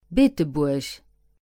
English: Pronunciation of the word "Beetebuerg" in Luxembourgish. Female voice.
Français : Prononciation du mot "Beetebuerg" en luxembourgeois. Voix féminine.